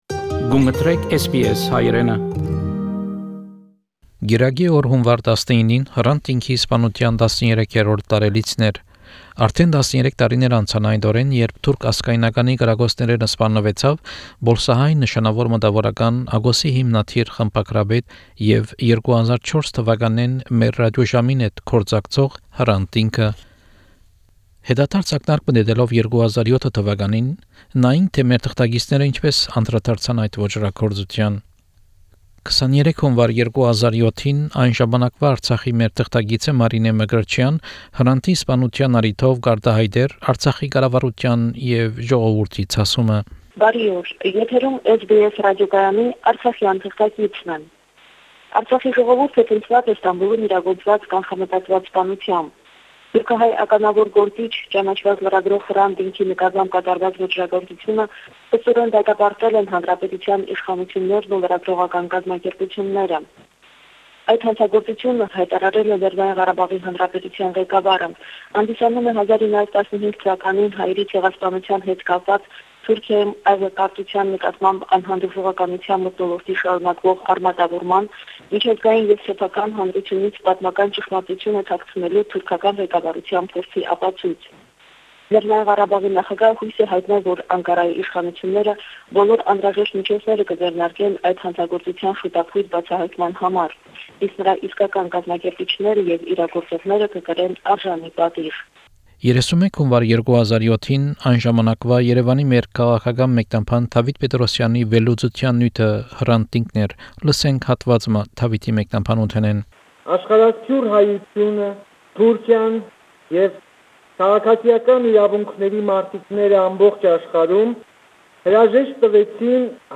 Հրանդ Տինքի յիշատակին նուիրուած այս հաղորդումը, կ'ընդգրկէ հատուածներ 2007 թուականի մեր թղթակիցներու տեղեկութիւններէն և հատուած մը մեր ռատիօժամին տուած Հրանդի վերջին հարցազրոյցէն Դեկտեմբեր 2006ին: